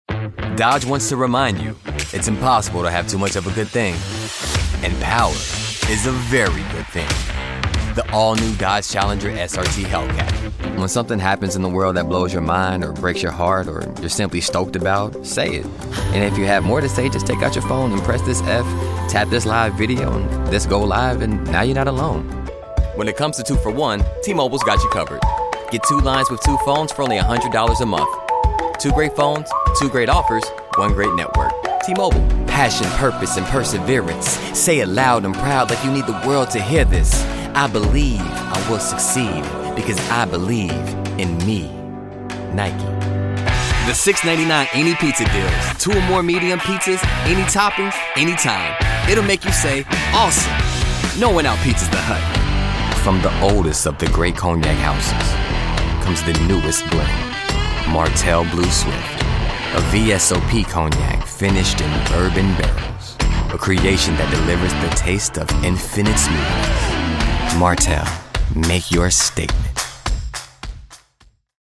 Now, Vibrant, straight talk.
Commercial